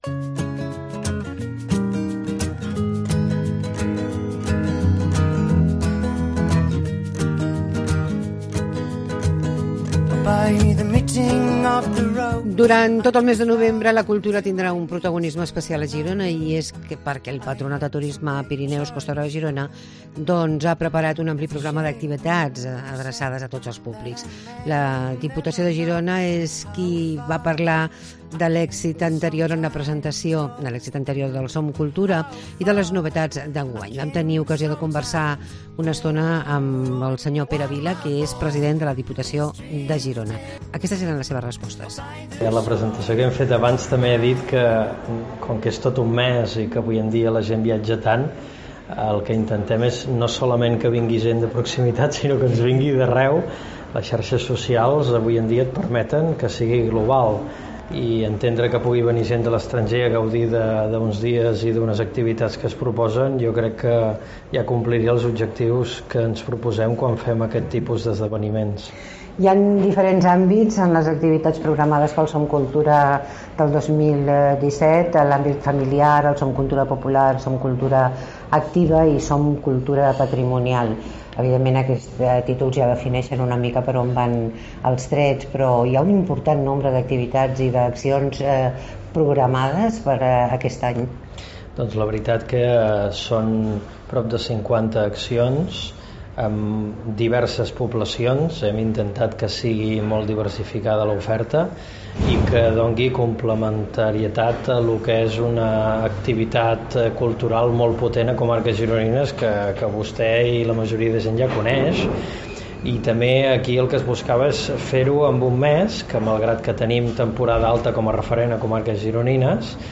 Comença una nova edició de Som Cultura. Entrevista